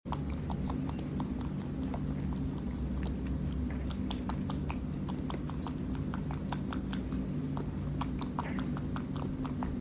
Cute bunny sound effects free download